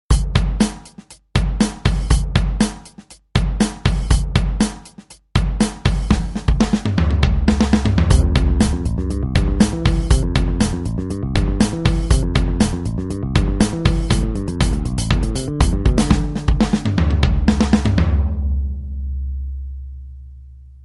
drum-bass_22143.mp3